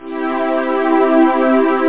Amiga 8-bit Sampled Voice
strings1.mp3